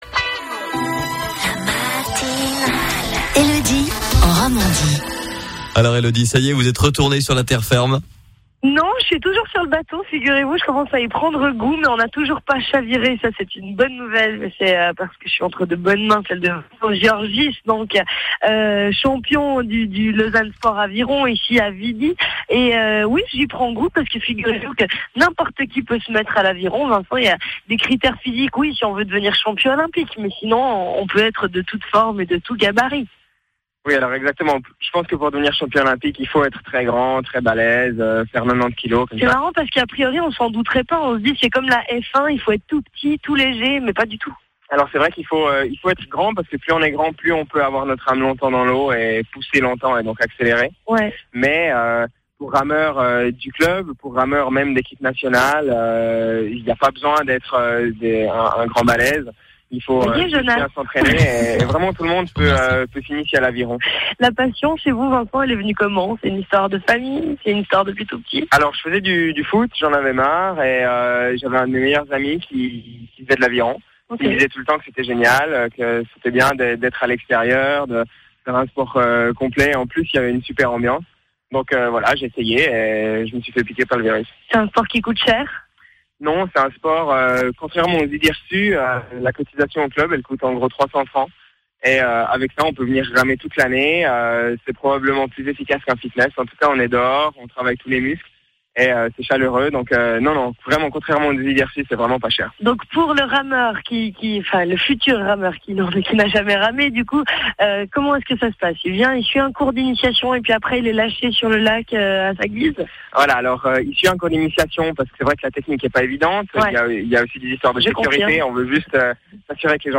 L'interview est en 4 parties.